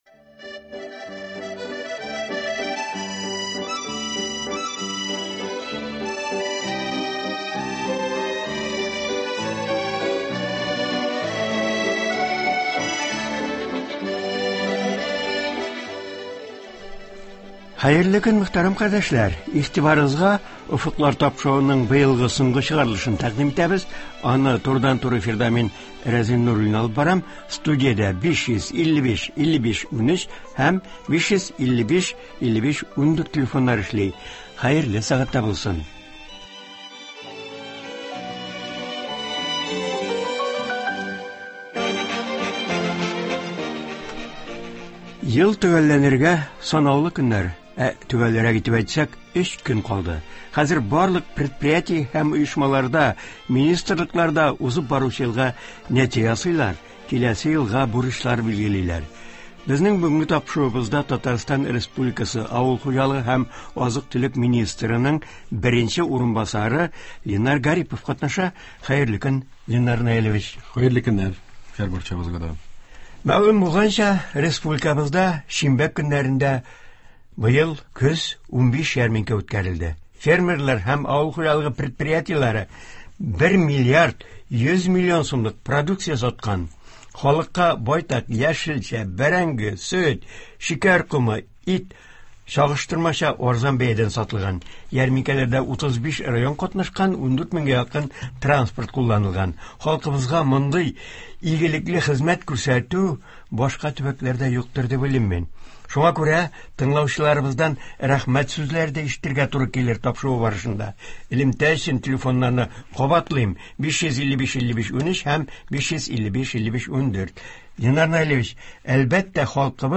Болар хакында турыдан-туры эфирда Татарстан республикасы авыл хуҗалыгы һәм азык-төлек министрының беренче урынбасары Ленар Наил улы Гарипов сөйләячәк, тыңлаучылар сорауларына җавап бирәчәк.